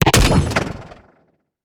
weapon_railgun_004.wav